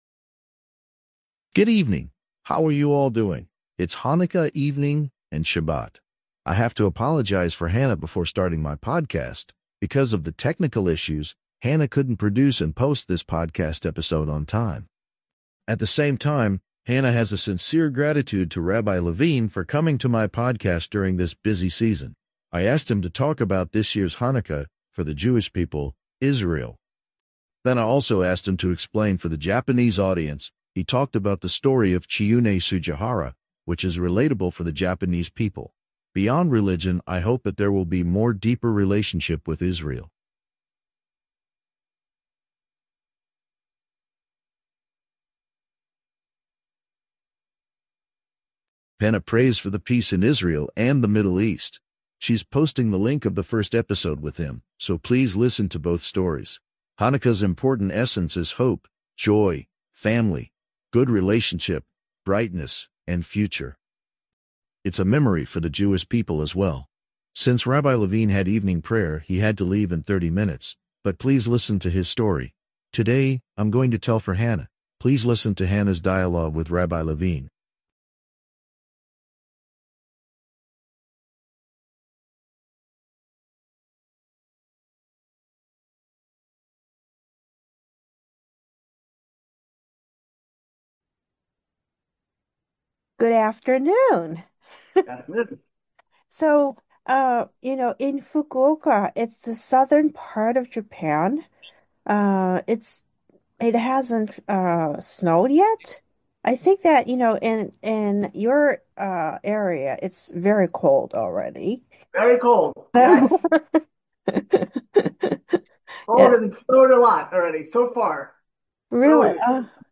“Scripps News Weekend” Interview